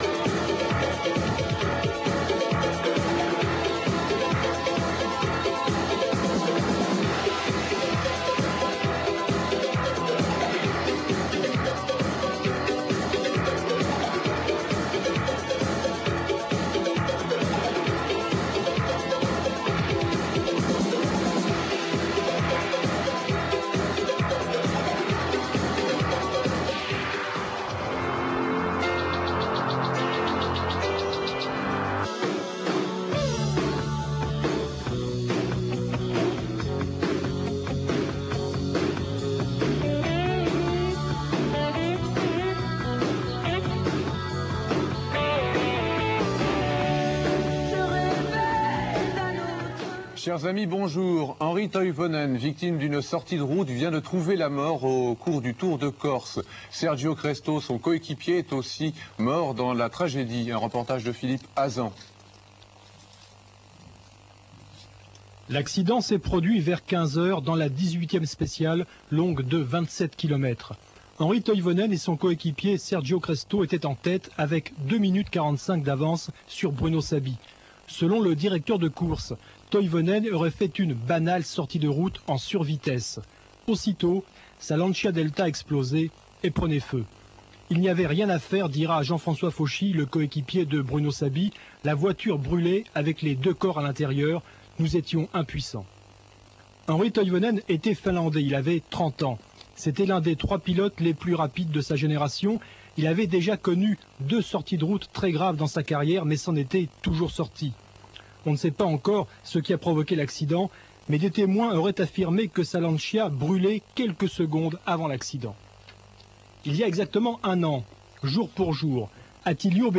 La qualité de la vidéo en streaming Real Multimedia est volontairement dégradée afin qu'elle soit rapidement téléchargée et affichée.